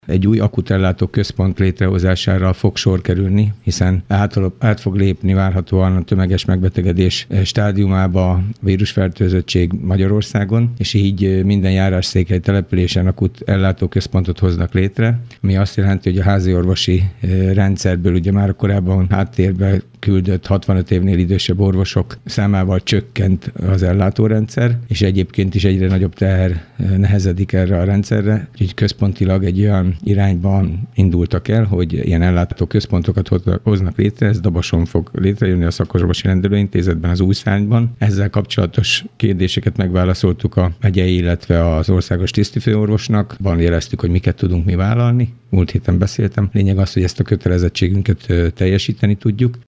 Akut ellátóközpontot hoznak létre Dabason. A járási központokban kötelező létrehozni olyan „akut betegellátó központokat”, ahol külön foglalkoznak majd a légúti, és nem légúti panaszokkal jelentkező páciensekkel. Kőszegi Zoltán polgármester arról beszélt rádiónknak, hol tart a városban ez a folyamat.